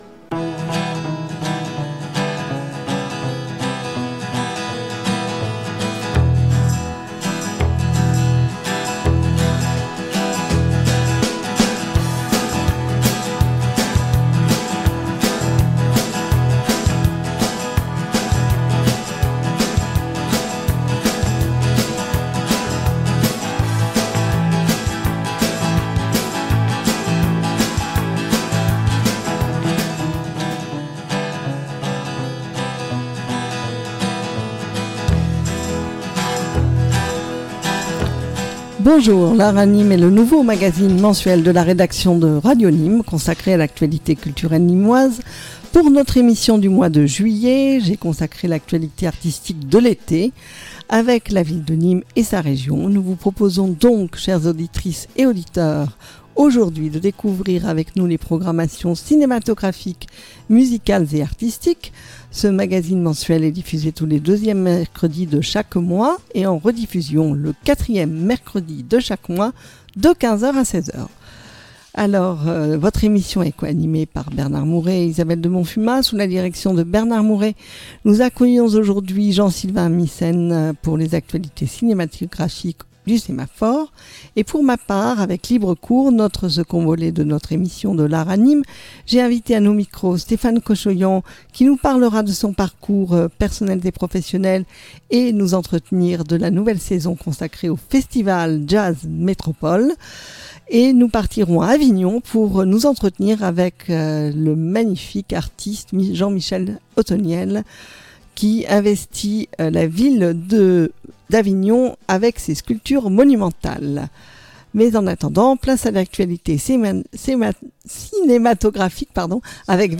Émission mensuelle « L’Art à Nîmes » - Mercredi 9 juillet 2025 de 15h00 à 16h00.